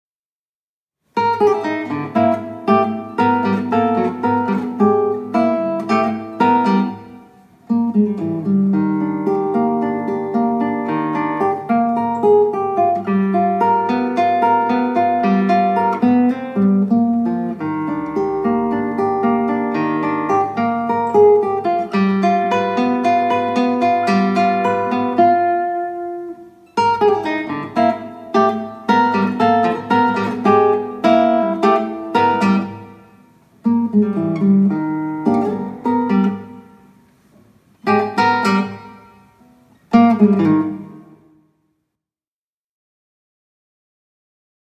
Andante Mosso   1:32